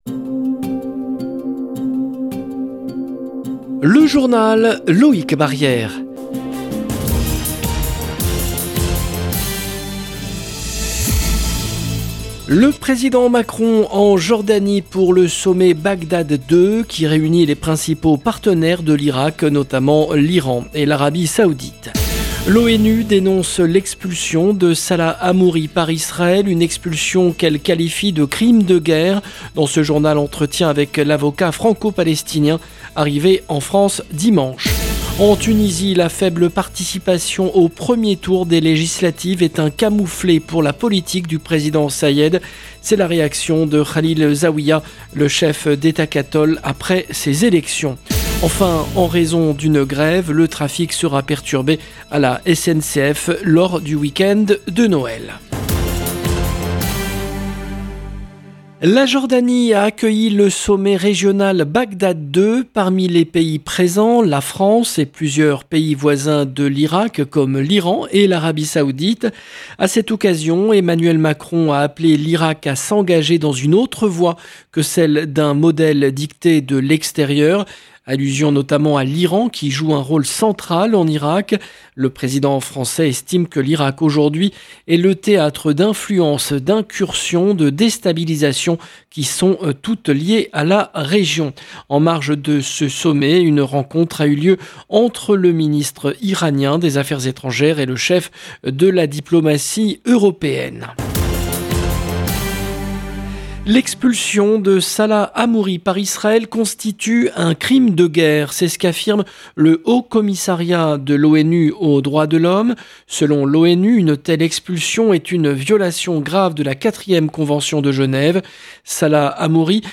LE JOURNAL EN LANGUE FRANCAISE DU SOIR DU 20/12/22
Irak Salah Hamouri Tunisie SNCF 20 décembre 2022 - 17 min 16 sec LE JOURNAL EN LANGUE FRANCAISE DU SOIR DU 20/12/22 LB JOURNAL EN LANGUE FRANÇAISE Le président Macron en Jordanie pour le sommet «Bagdad II», qui réunit les principaux partenaires de l’Irak, notamment l’Iran et l’Arabie saoudite. L’ONU dénonce l’expulsion de Salah Hamouri par Israel, une expulsion qu’elle qualifie de “crime de guerre”. Dans ce journal, entretien avec l’avocat franco-palestinien arrivé en France dimanche.